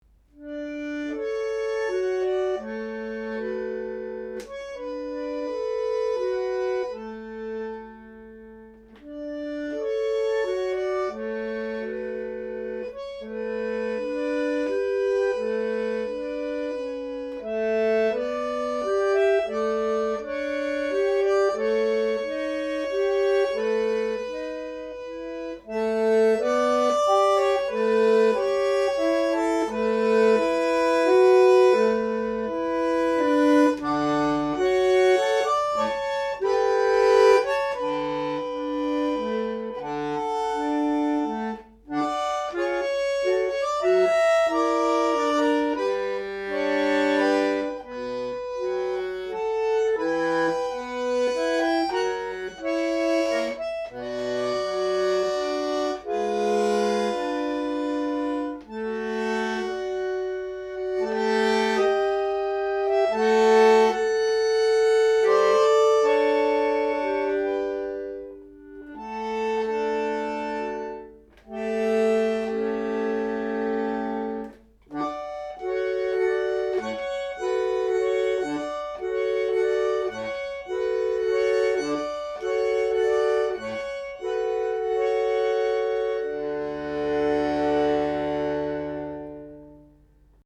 bandoneonBandoneon